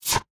Select Scifi Tab 3.wav